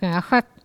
Cri pour chasser le chat ( prononcer le crti )